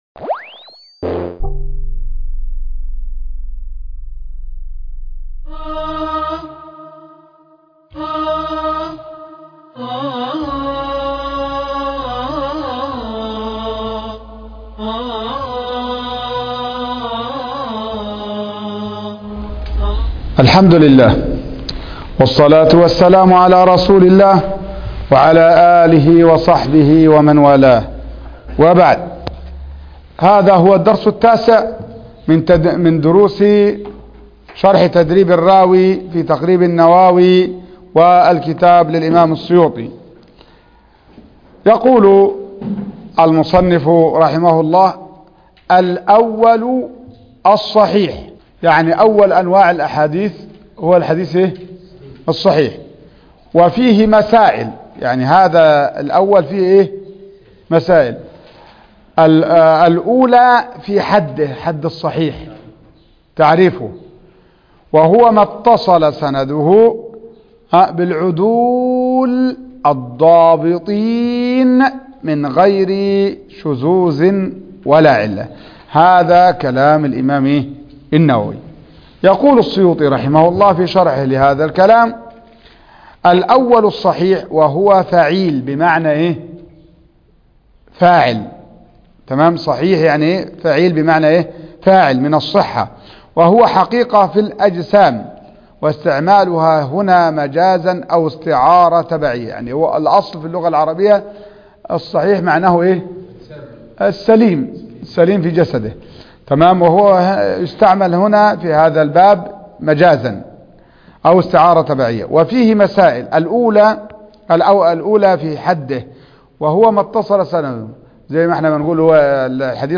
الدرس التاسع - تدريب الراوي فى شرح تقريب النواوي